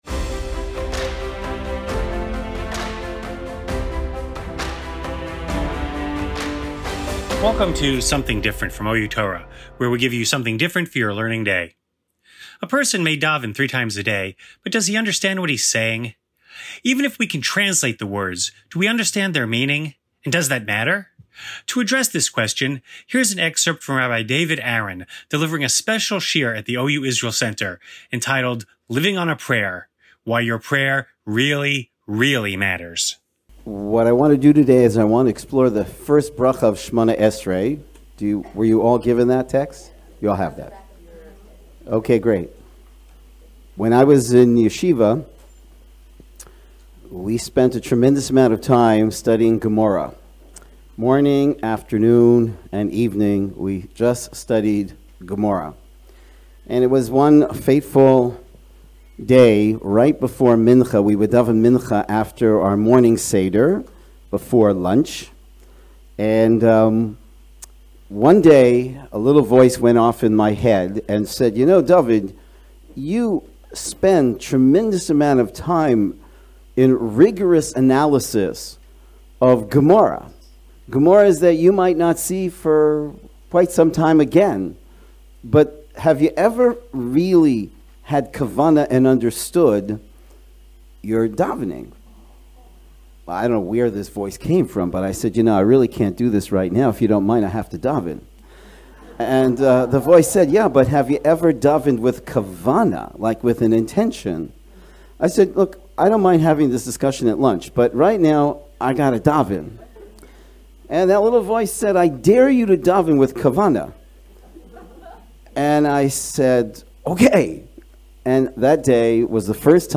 delivered at the OU Israel Center.